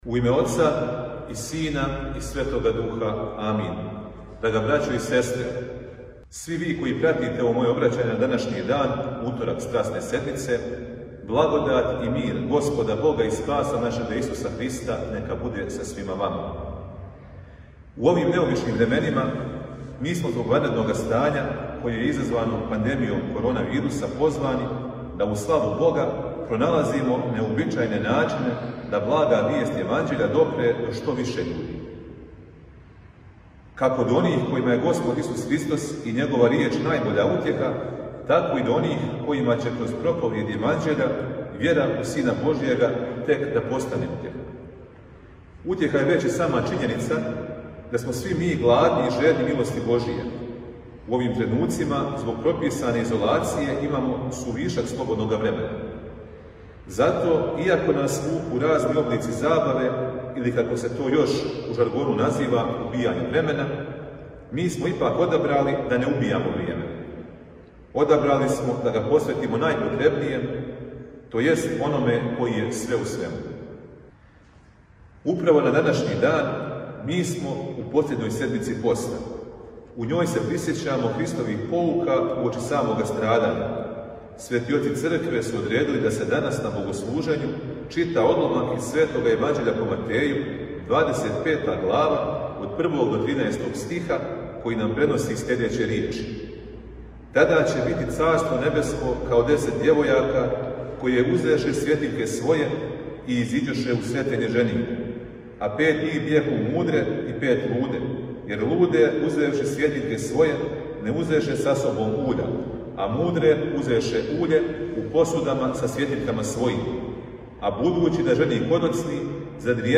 Звучни запис беседе Само када превазиђемо самољубље можемо очекивати да се у наше светиљке почне сабирати уље које се неће потрошити никада.